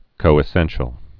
(kōĭ-sĕnshəl)